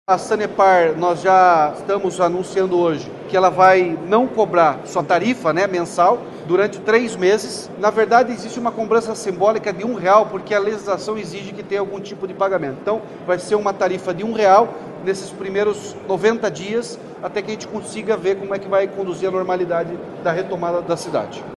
Sonora do governador Ratinho Junior sobre a isenção da tarifa de água para moradores de Rio Bonito do Iguaçu